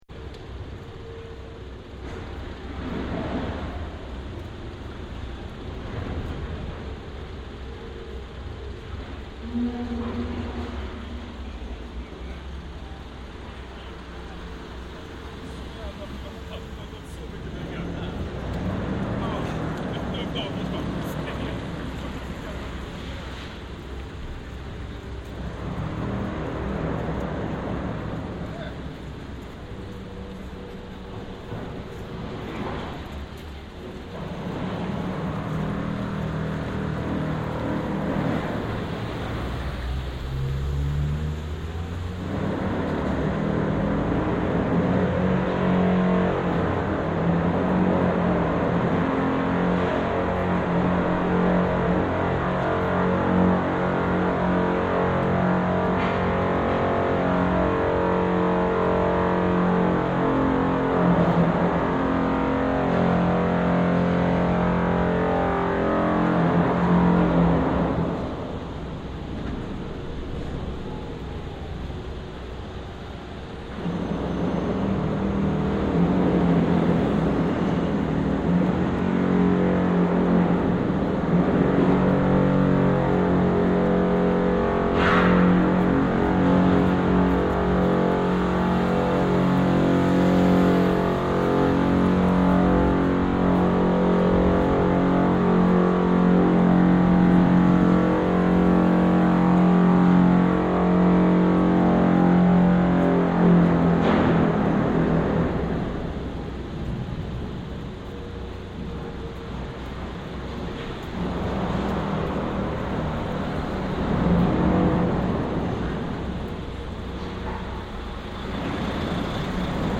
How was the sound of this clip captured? Downtown Stockholm